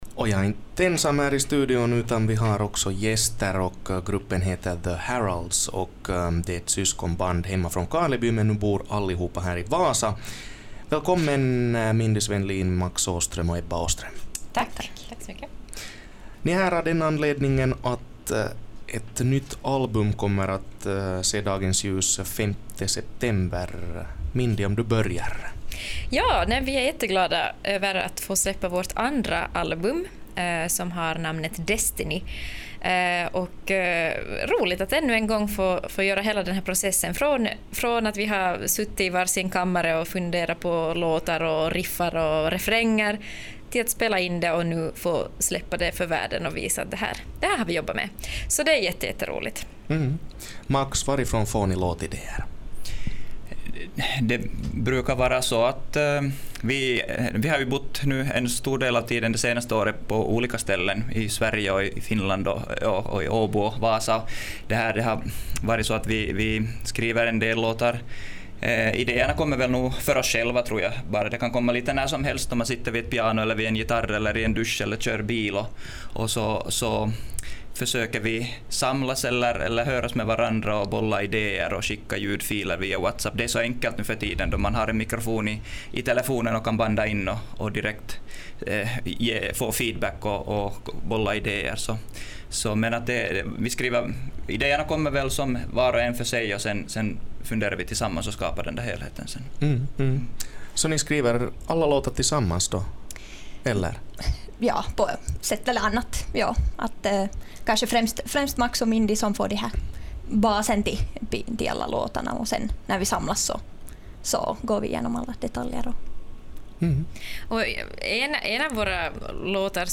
Gruppen gästade livestudion.